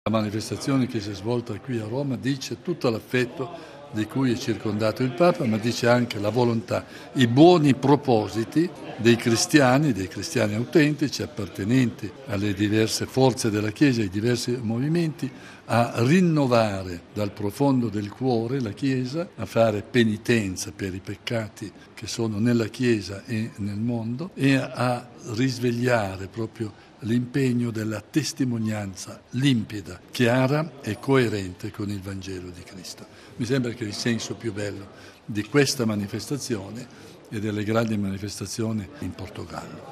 ◊   "Insieme con il Papa": è la scritta che stamani campeggiava su uno dei tanti striscioni portati dai fedeli in Piazza San Pietro.